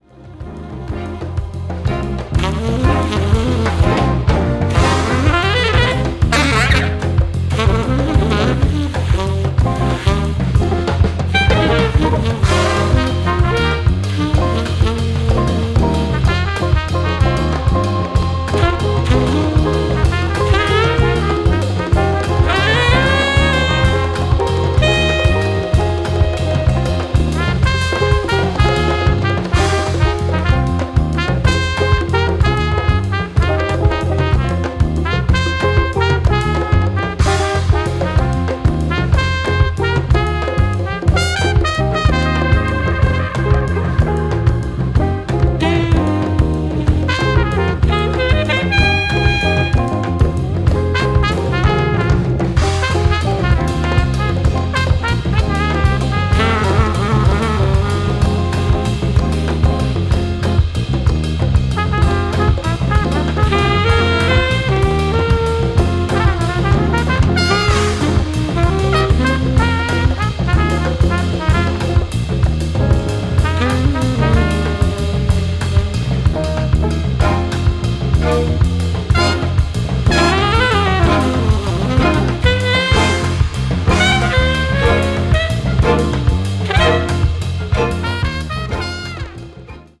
全編通して温かく豊かな味わいに満ちた仕上がりとなっています。